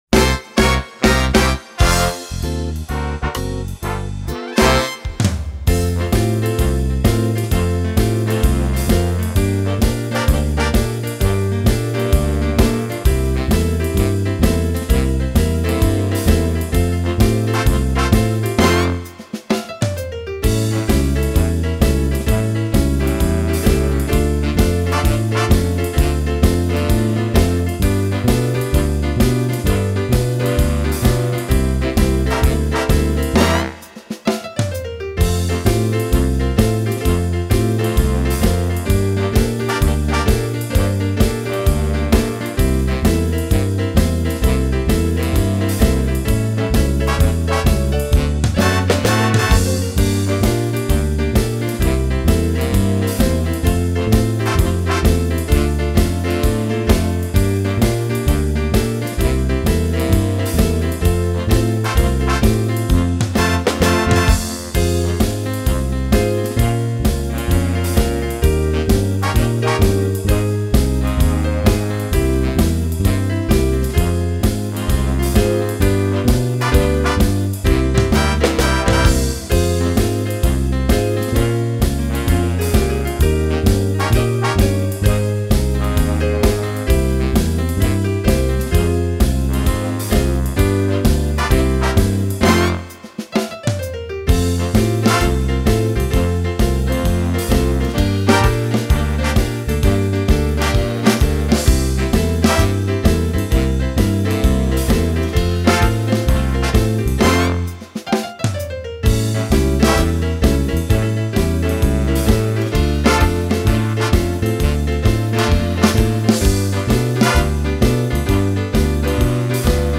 Madison